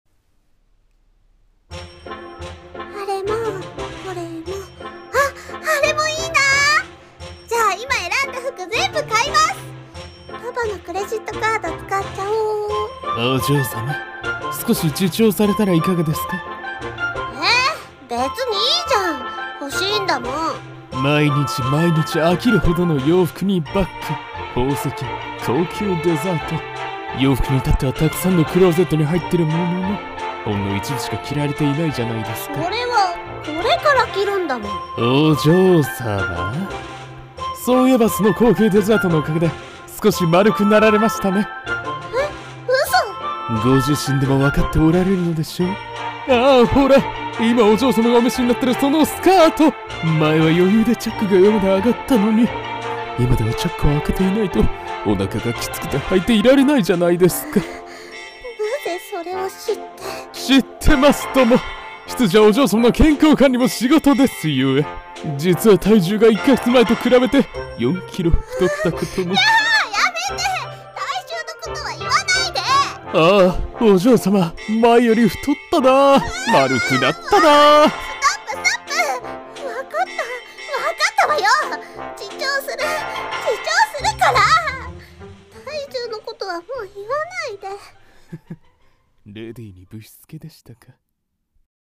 【声劇】 自重してください【掛け合い】